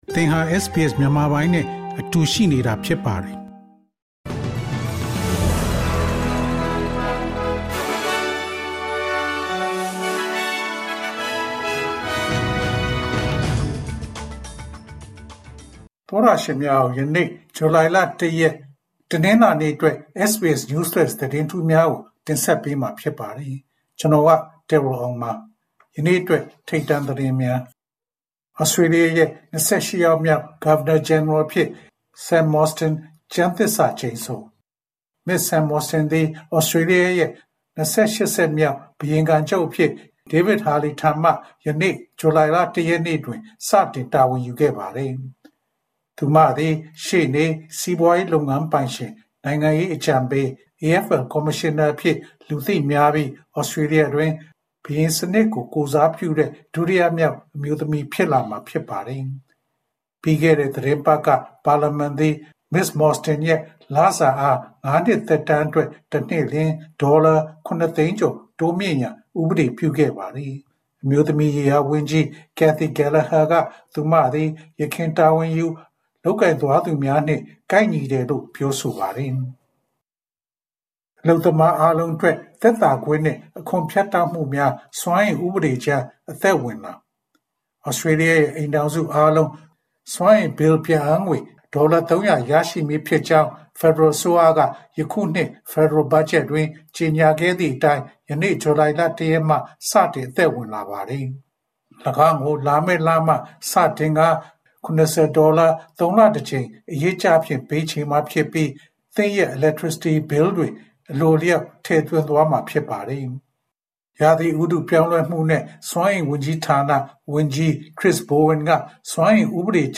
ALC: SBS မြန်မာ ဇူလိုင်လ ၁ ရက် News Flash သတင်းများ။